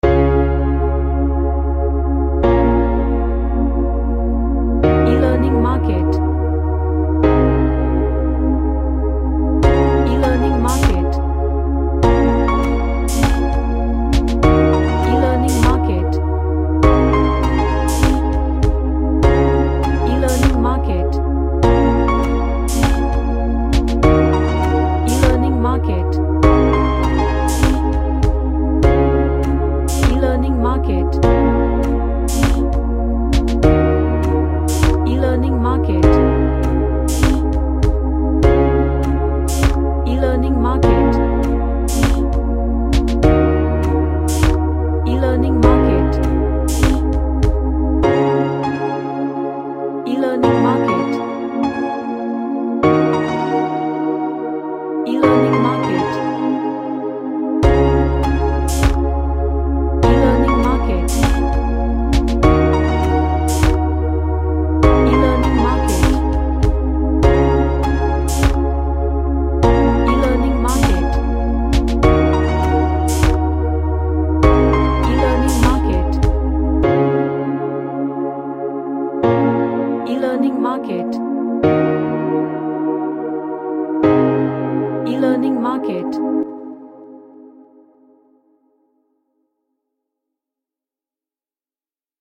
A relaxing track.
Happy